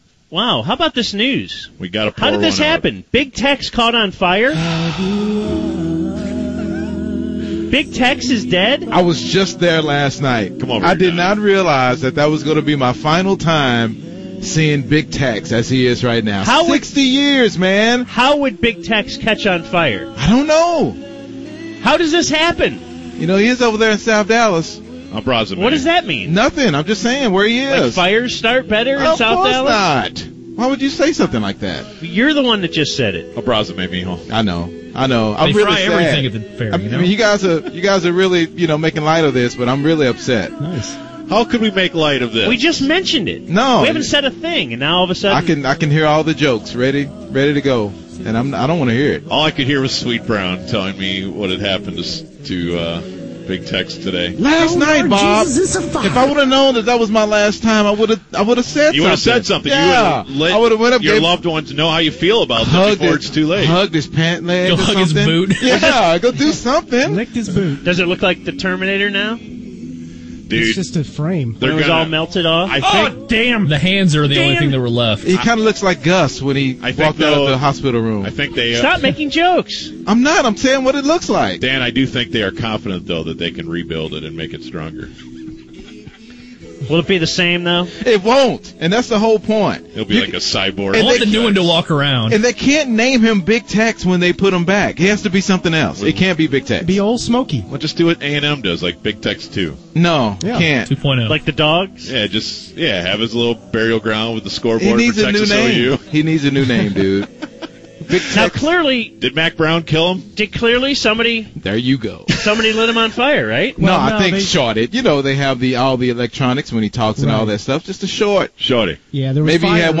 The BaDD radio boys discuss the days most important State Fair of Texas news 2012.